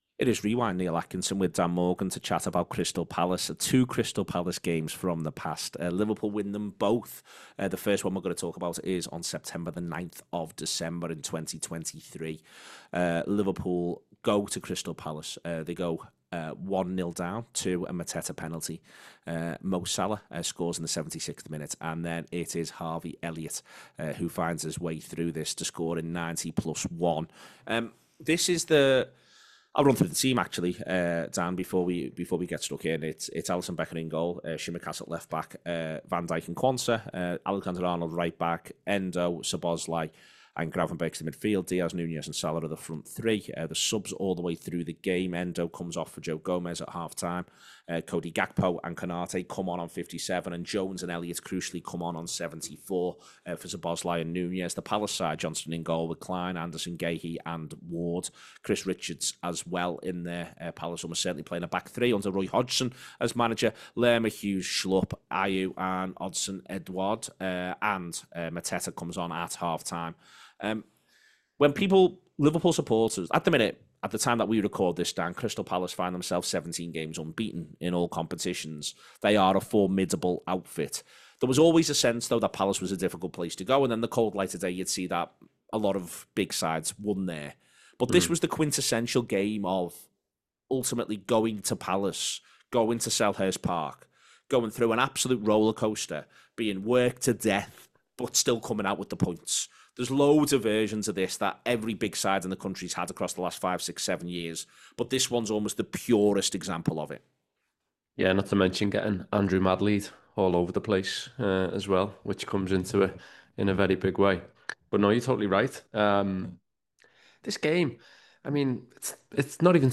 Below is a clip from the show – subscribe to The Anfield Wrap for more Liverpool chat…